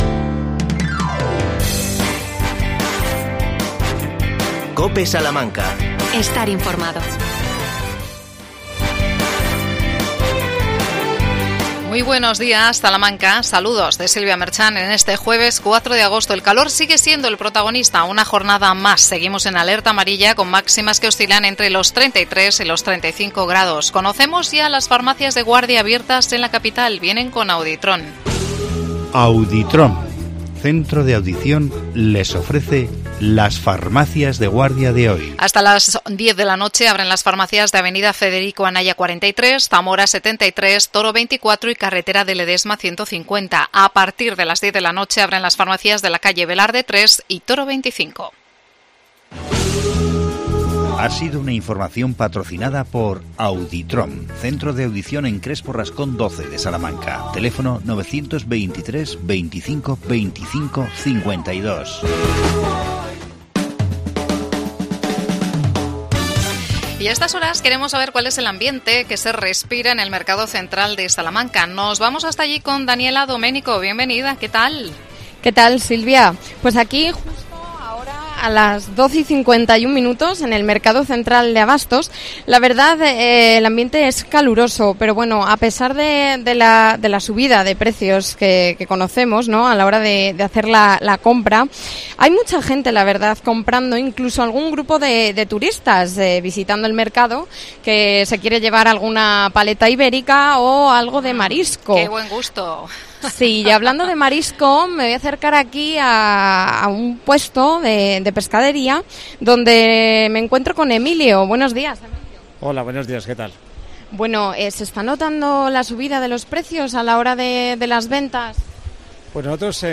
AUDIO: Hablamos de los precios de los alimentos desde el mercado central de Salamanca. Dieta saludable en verano.